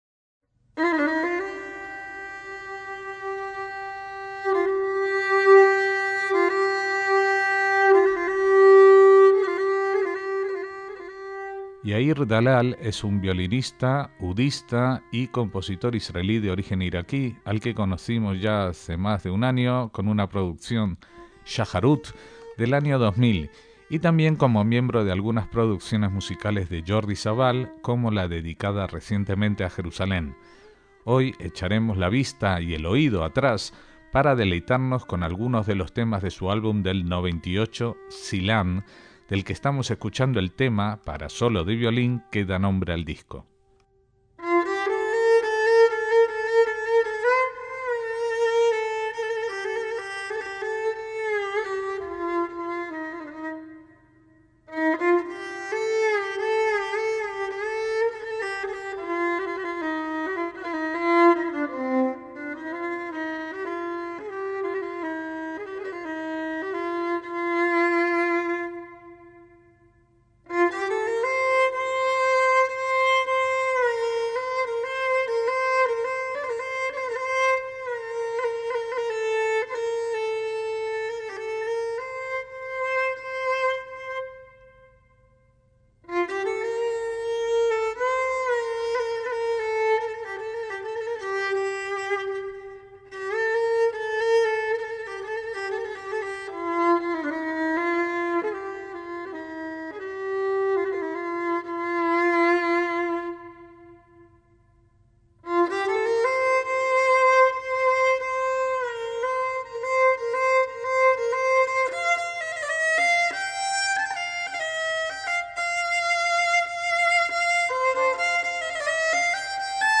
laúd árabe y violín oriental
clarinete y flautas
tabla, tampura y voz
sitar y guitarra
percusión